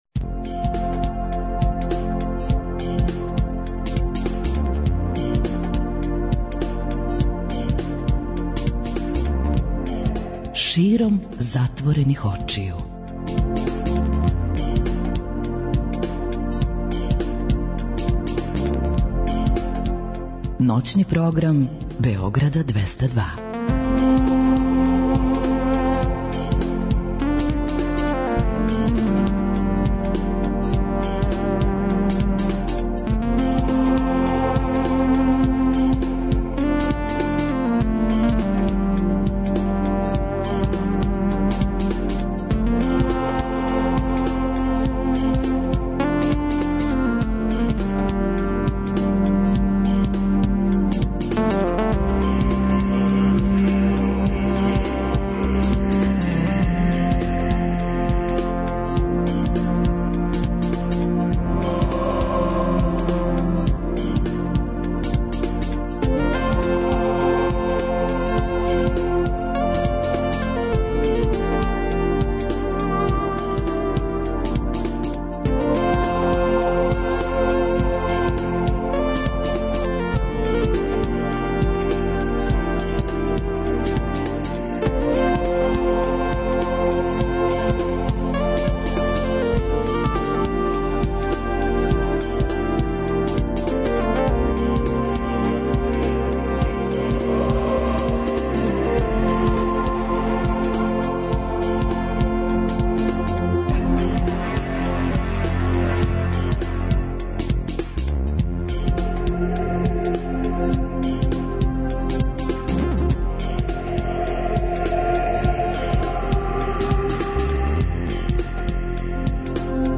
преузми : 41.90 MB Широм затворених очију Autor: Београд 202 Ноћни програм Београда 202 [ детаљније ] Све епизоде серијала Београд 202 Блузологија Свака песма носи своју причу Летње кулирање Осамдесете заувек!